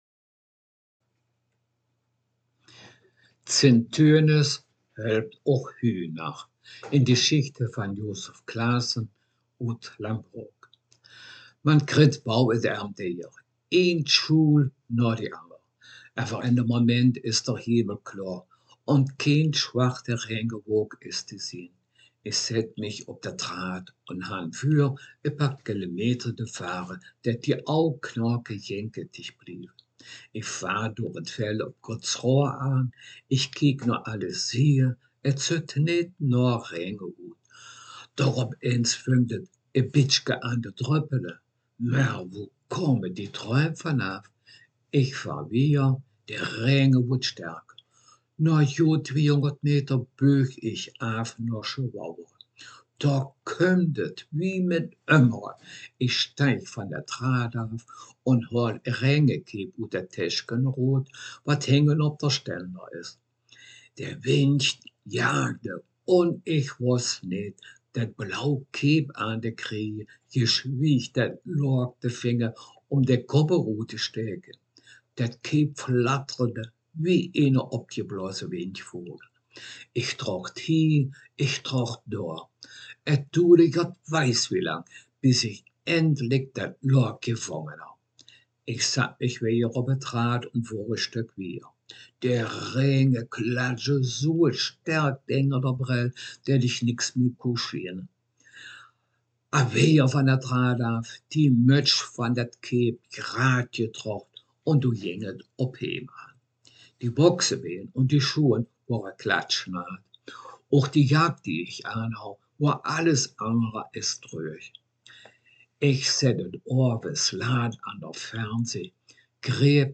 Text Mundart